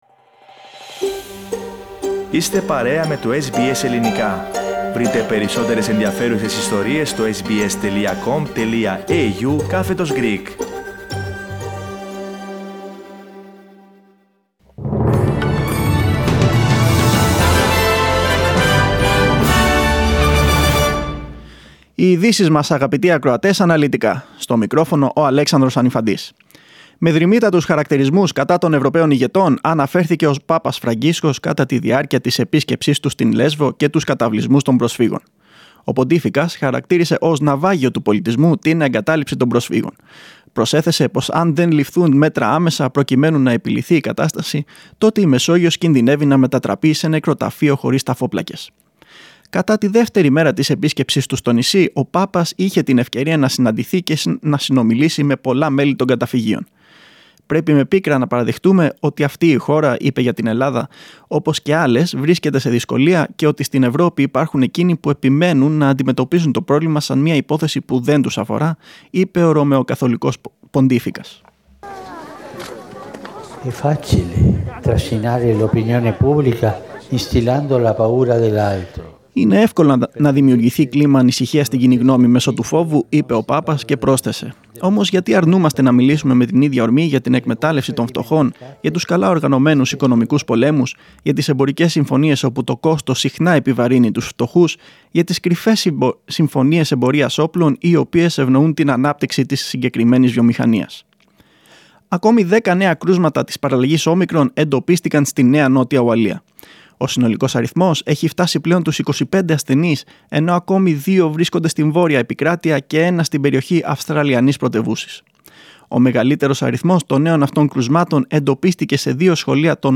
Δελτίο Ειδήσεων 6.12.21